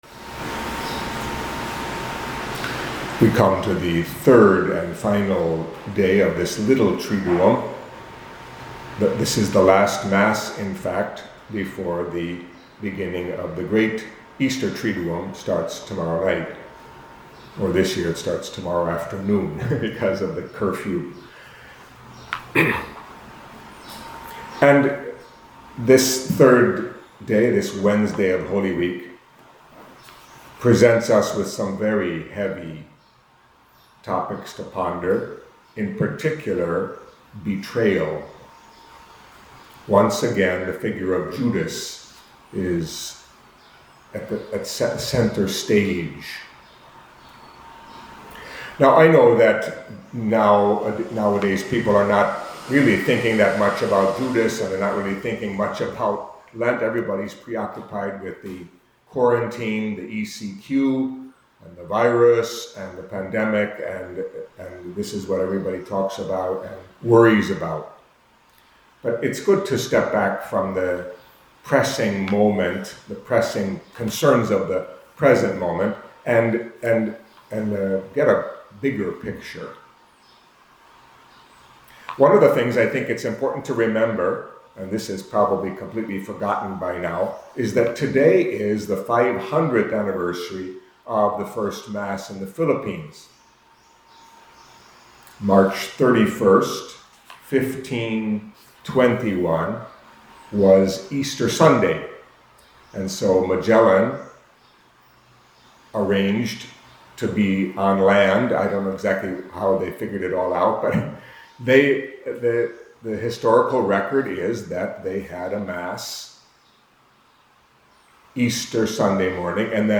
Catholic Mass homily for Wednesday of Holy Week